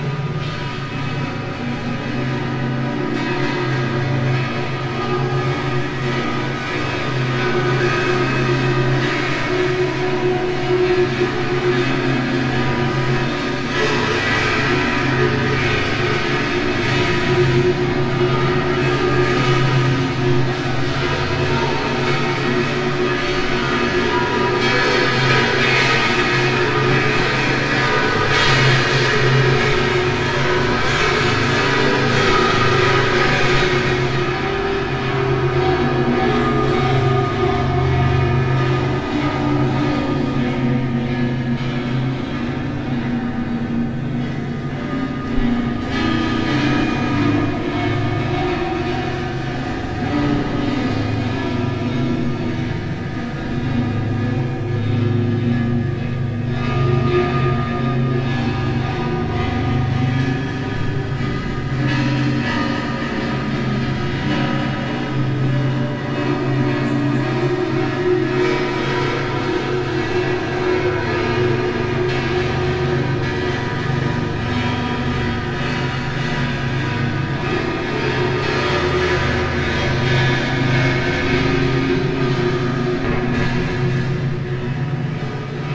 描述：这是Danny Phantom走向幽灵时的声音！我被告知它也类似于Yu Yu Hakasho开场时的声音效果。 声音只是两个白噪声相互叠加，一个在音高上下降，一个在音高上有一点共鸣。
标签： 改造 卡通 动漫 丹尼 - 幻影 白噪声 变换 魔术 去鬼
声道立体声